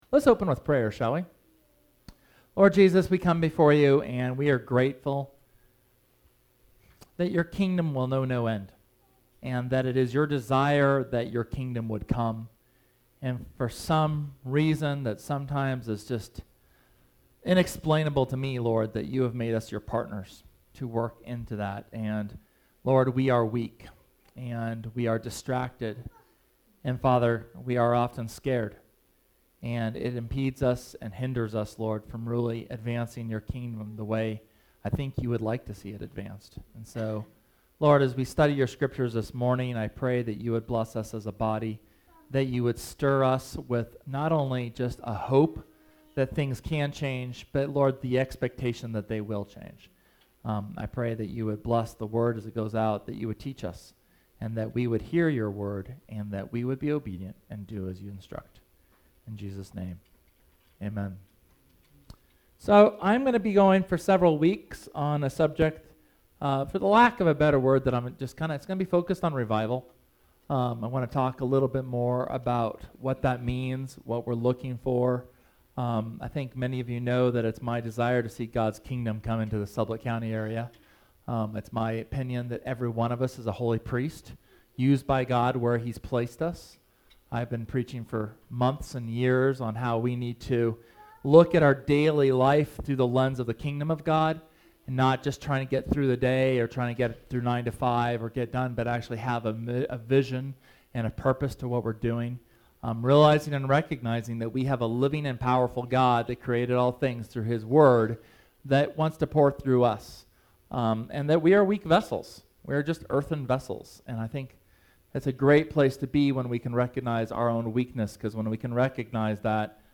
SERMON: Revival (1)
Sermon from July 24th on the characteristics and need for revival and reformation.